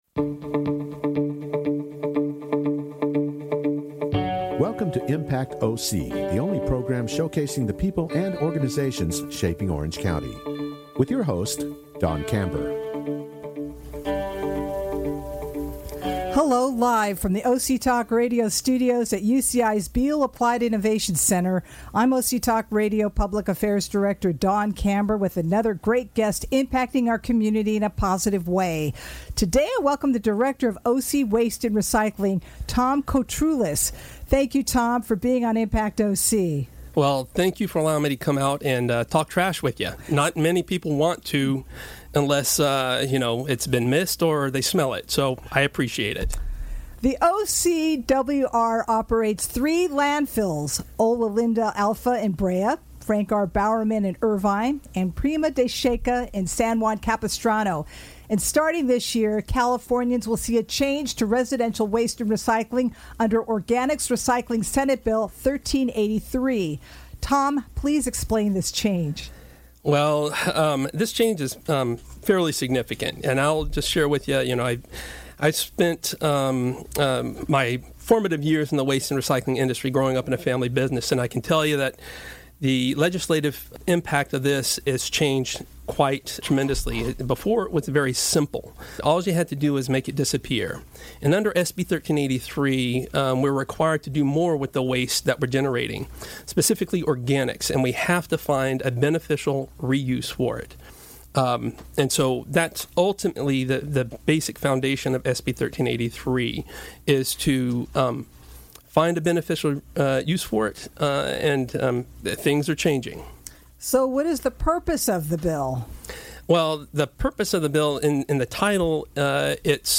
He talks about the Organics Recycling Senate Bill 1383. And what that means for residents as they must sort out their trash into different bins. Only on OC Talk Radio, Orange County’s Only Community Radio Station which streams live from the University of California-Irvine’s BEALL APPLIED INNOVATION CENTER.